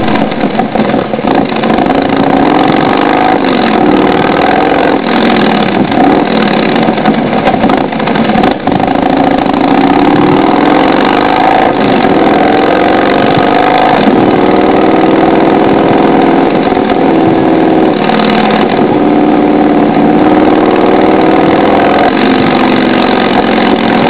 Sound Library Each sound comes with a main loop and two fire button sounds (as shown in parentheses). The audio is a short demo version of the sound.
HARLEY.WAV